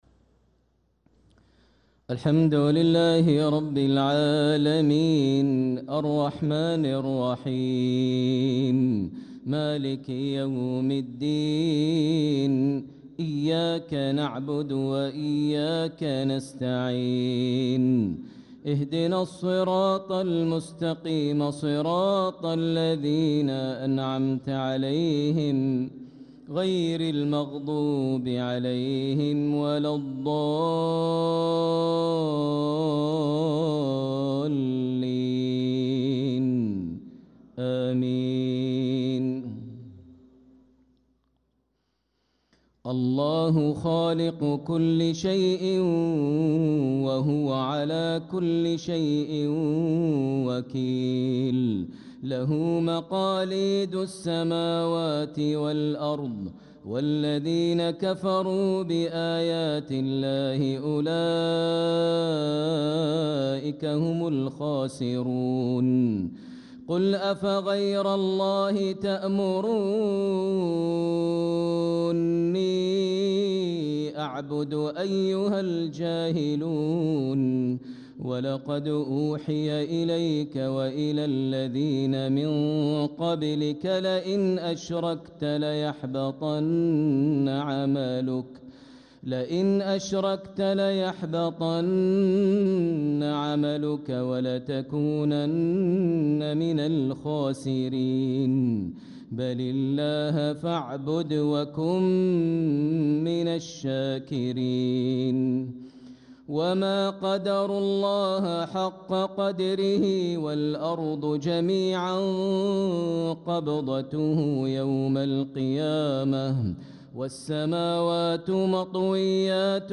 صلاة العشاء للقارئ ماهر المعيقلي 28 جمادي الأول 1446 هـ